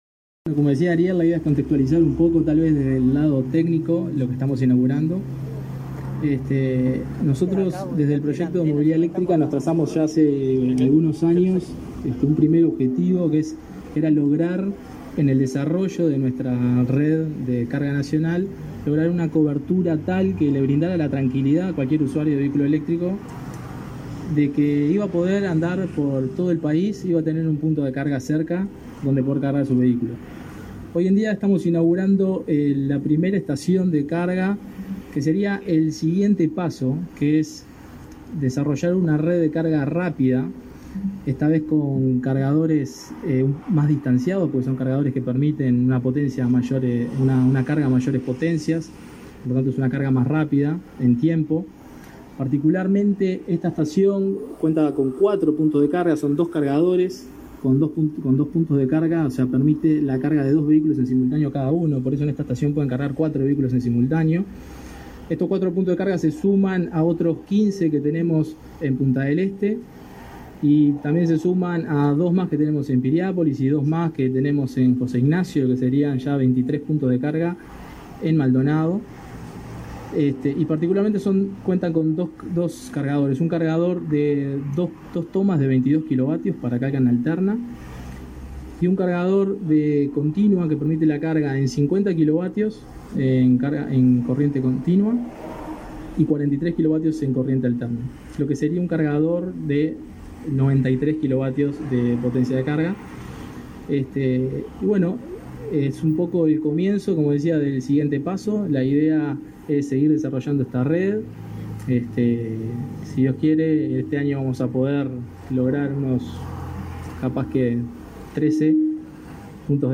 Conferencia de prensa por la inauguración de un punto de carga para vehículos eléctricos en Punta del Este